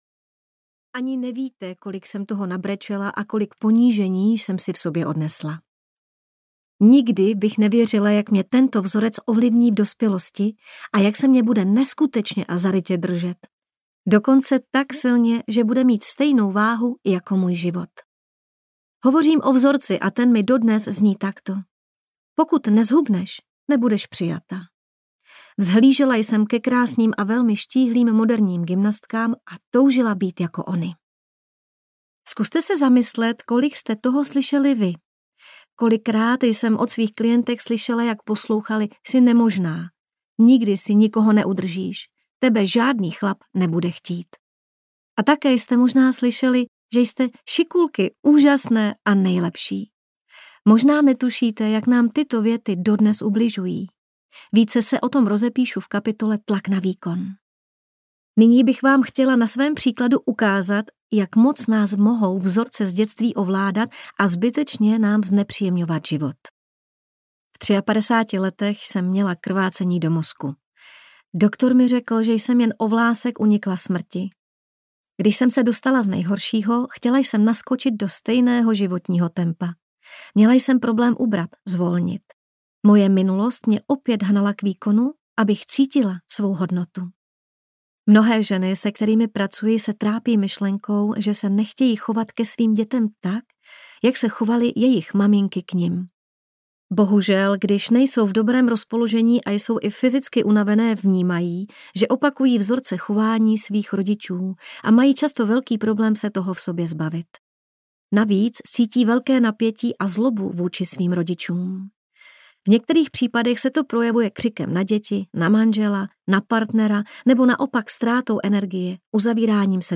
Kniha pro ženy audiokniha
Ukázka z knihy